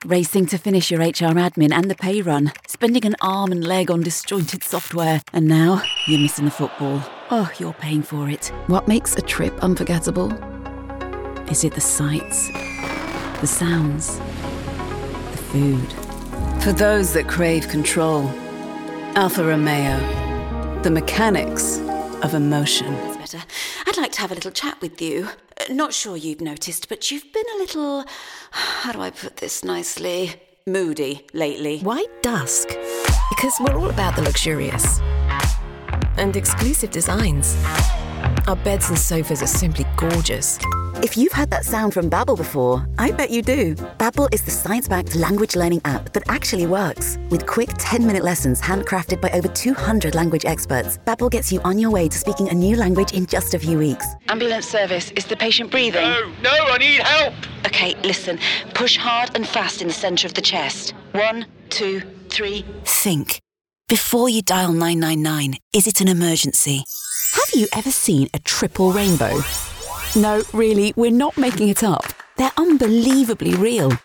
Adult
Has Own Studio
COMMERCIAL 💸